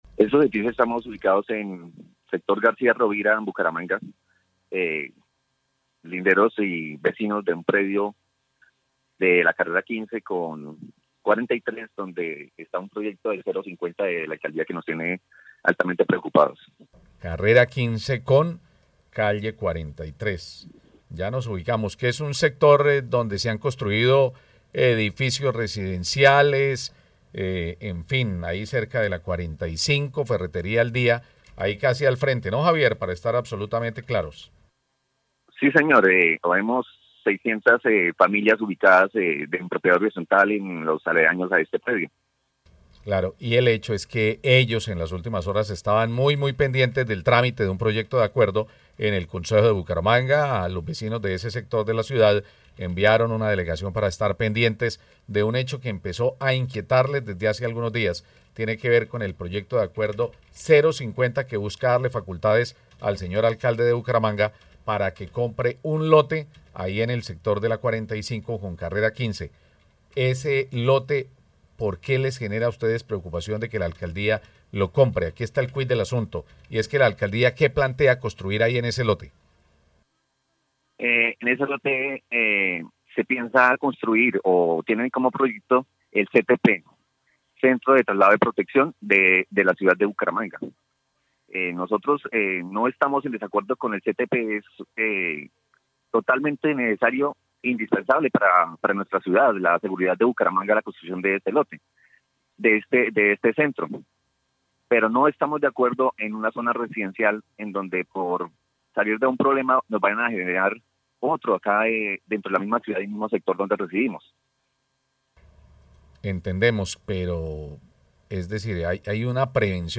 En diálogo con Caracol Radio, explicó que les inquieta que cerca de sus hogares vaya a funcionar una institución a donde lleven a habitantes de calle; personas que padecen problemas mentales y borrachos.